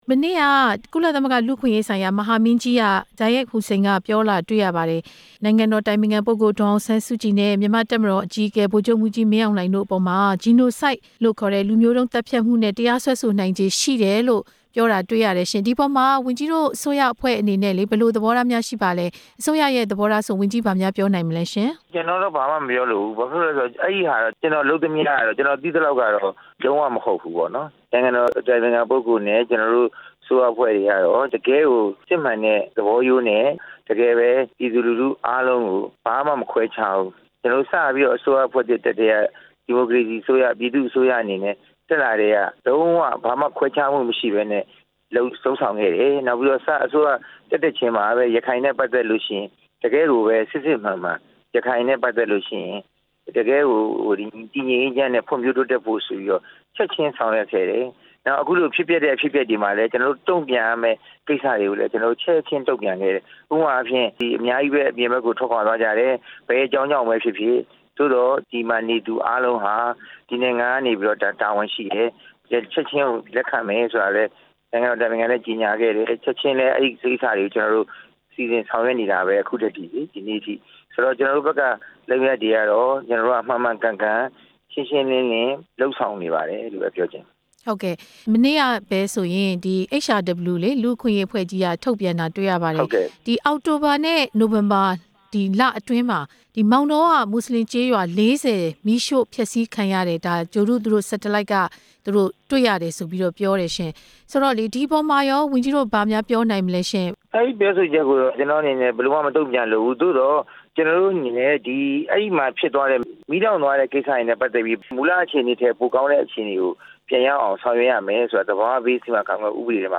လူမျိုးတုံးသတ်ဖြတ်မှုနဲ့ တရားစွဲဆိုနိုင်ကြောင်း ကုလပြောဆိုမှု ဒေါက်တာဝင်းမြတ်အေး နဲ့ မေးမြန်းချက်